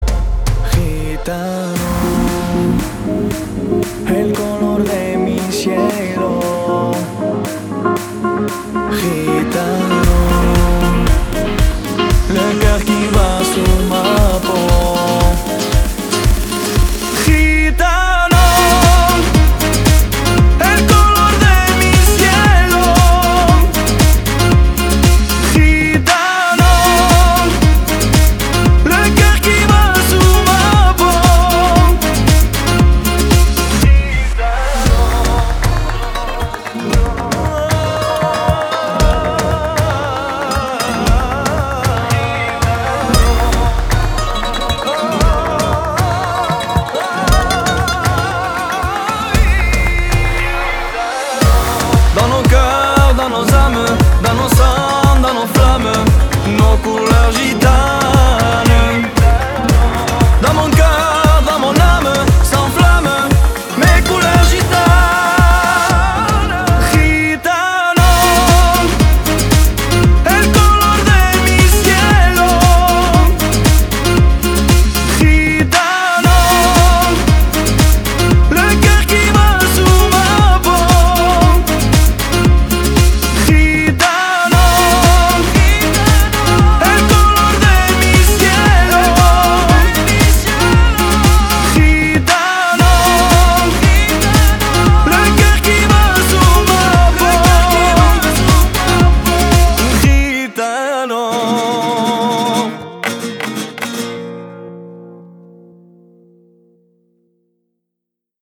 • Качество: 320, Stereo
поп
мужской вокал
dance
восточные